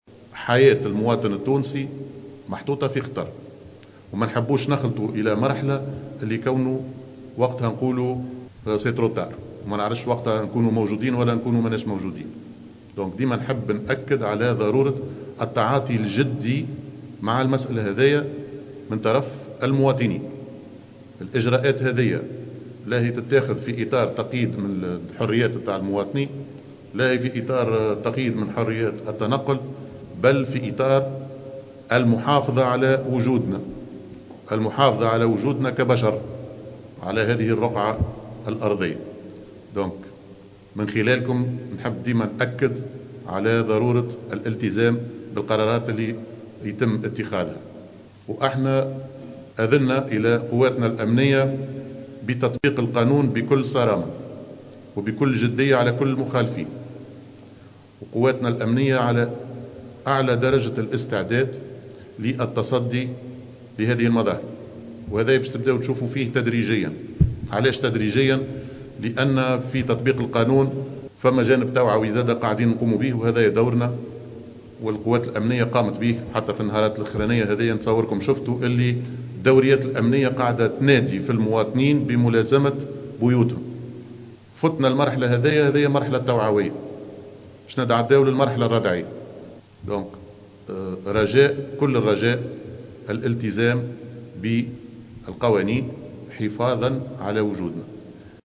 أكدّ وزير الداخلية هشام المشيشي في ندوة صحفية عقدها منذ قليل، أن الوزارة لن تتردد في تطبيق القانون بكل جدية و صرامة، ضدّ كل من يُخالف تراتيب حظر الجولان و الحجر الصحي العام.